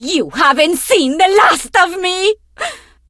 diva_die_vo_03.ogg